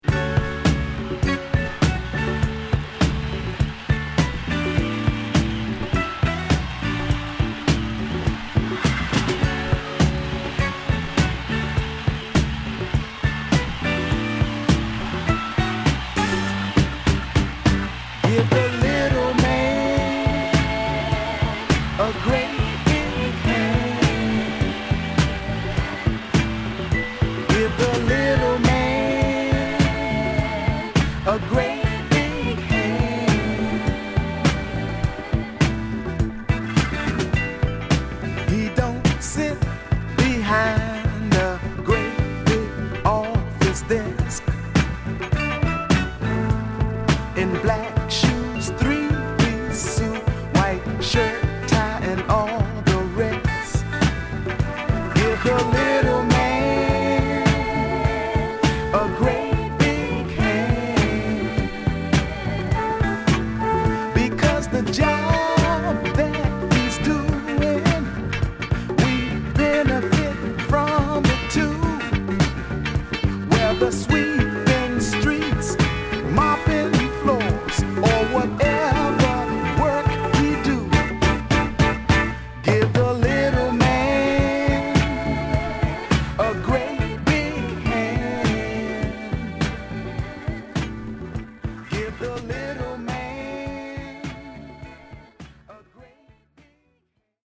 (Stereo)